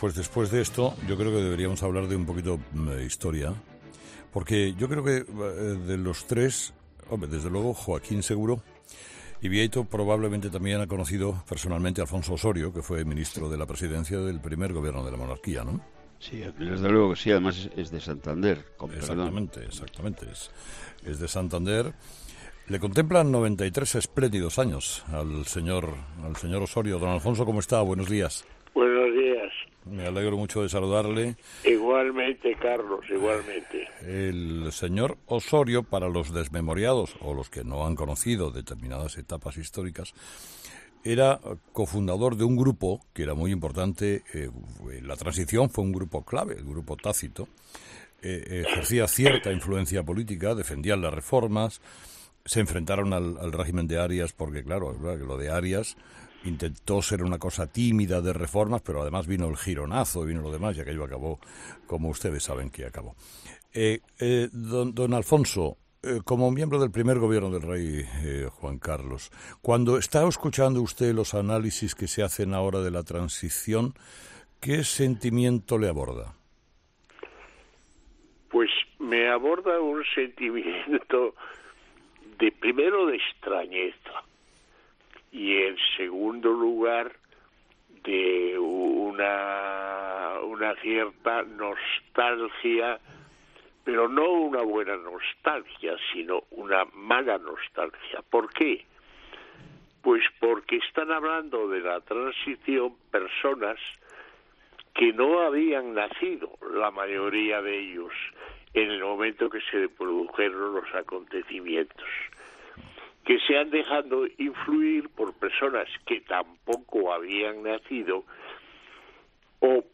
Alfonso Osorio, ministro de la Presidencia del primer gobierno democrático, en 'Herrera en COPE'